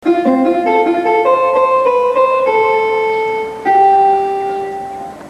Znělky:
znělka Košice.mp3